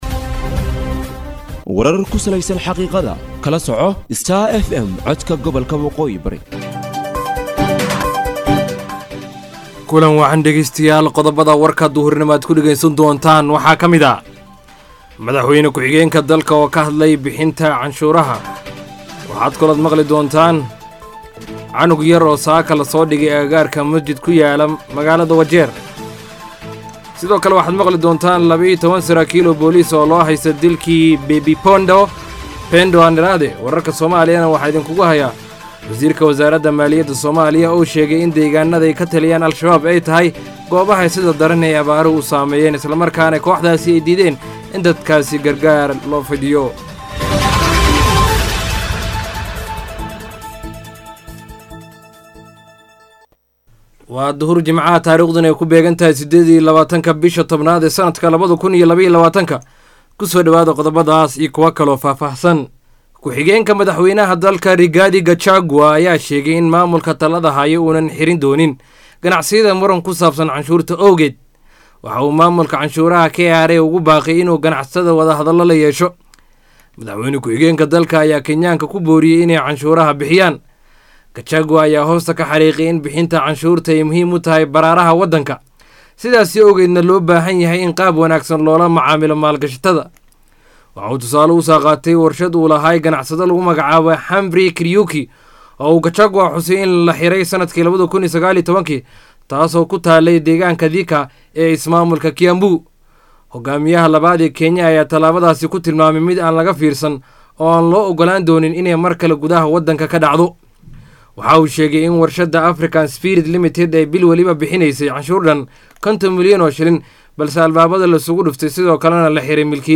DHAGEYSO:WARKA DUHURNIMO EE IDAACADDA STAR FM
NEWS-28-OCT-130PM-.mp3